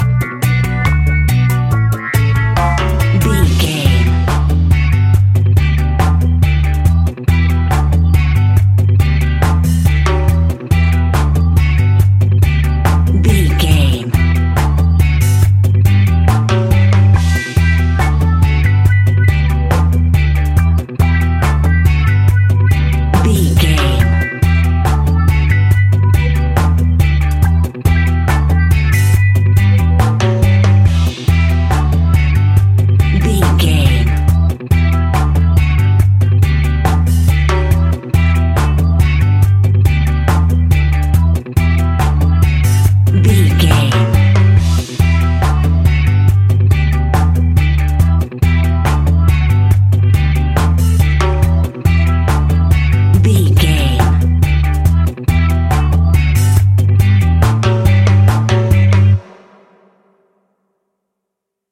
Classic reggae music with that skank bounce reggae feeling.
Aeolian/Minor
reggae instrumentals
laid back
chilled
off beat
drums
skank guitar
hammond organ
percussion
horns